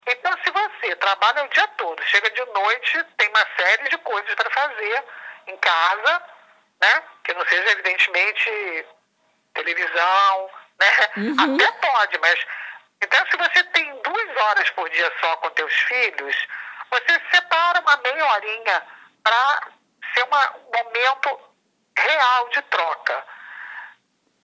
Entrevista especial com Tania Zagury- FOTO TANIA